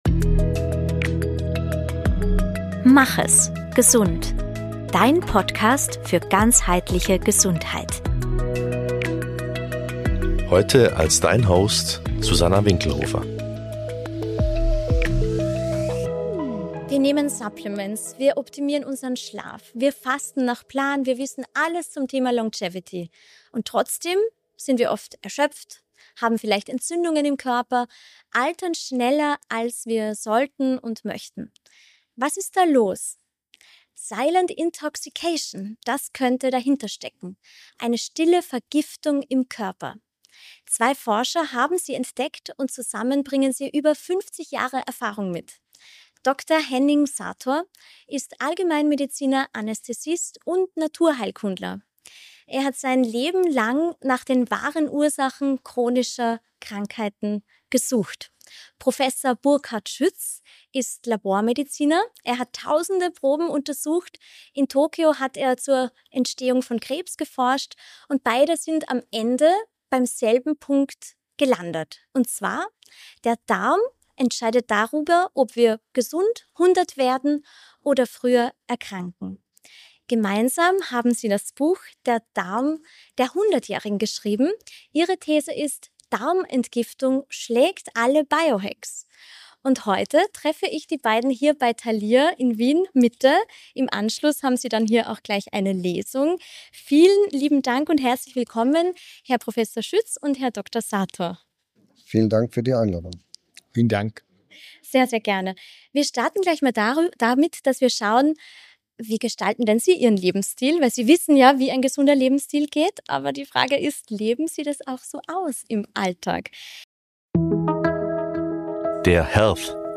Wir treffen die beiden Autoren in Wien.